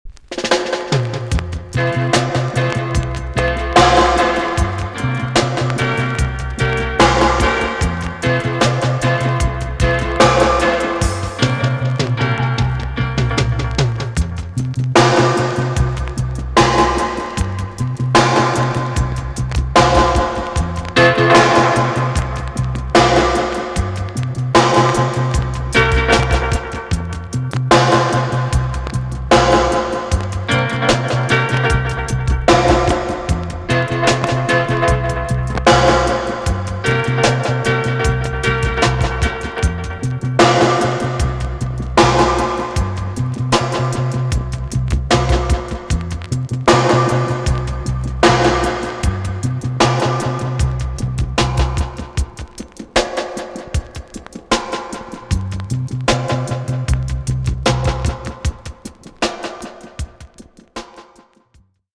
TOP >80'S 90'S DANCEHALL
VG+ 少し軽いチリノイズがあります。